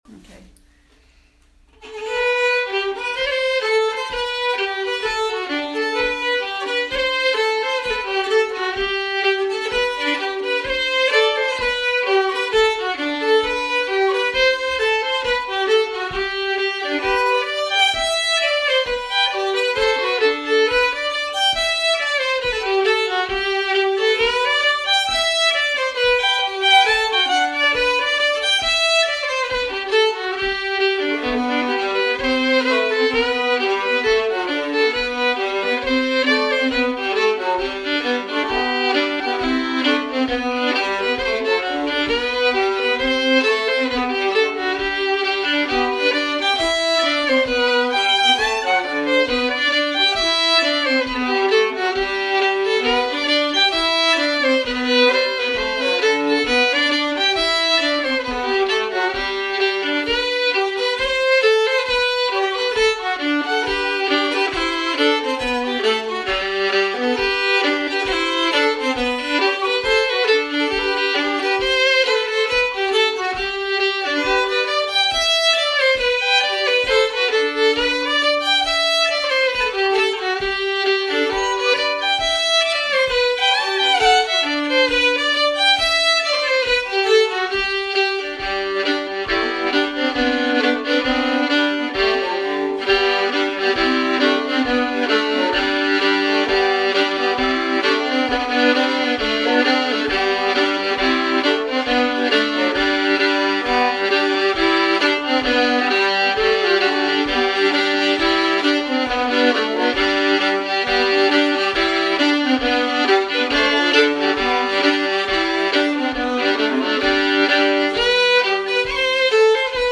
She added some lovely variations and I played the tune either in the usual violin range or an octave lower (I have a five string fiddle). All the mistakes are mine, but you can hear some of the ideas…